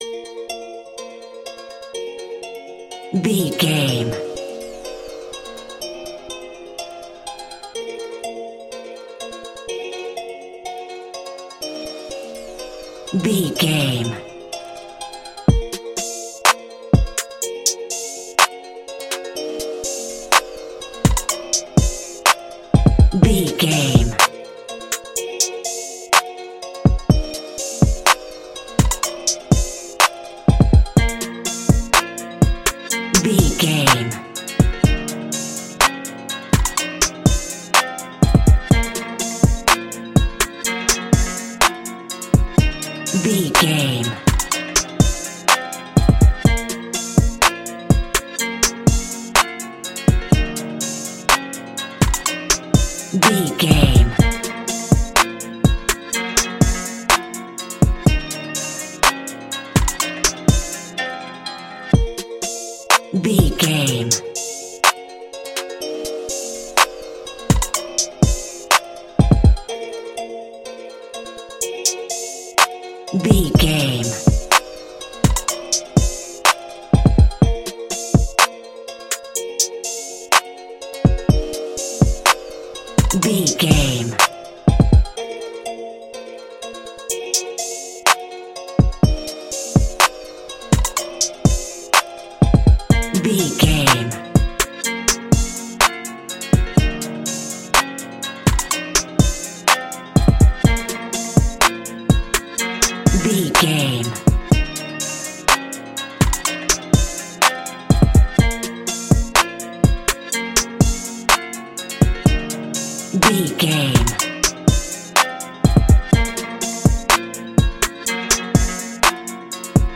Aeolian/Minor
aggressive
intense
driving
dark
drum machine
synthesiser
strings